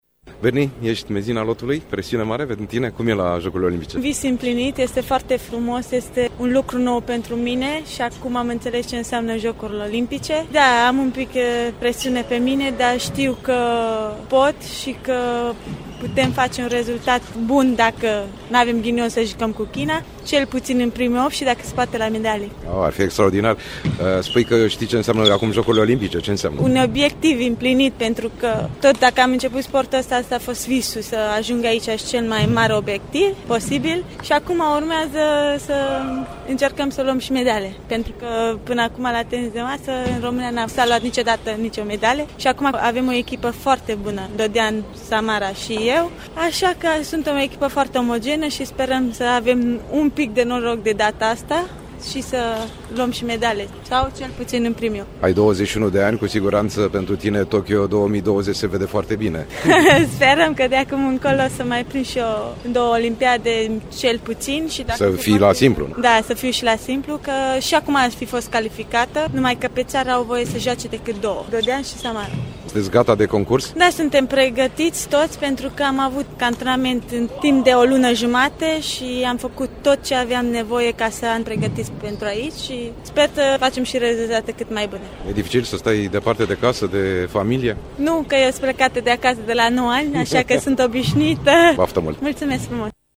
interviul audio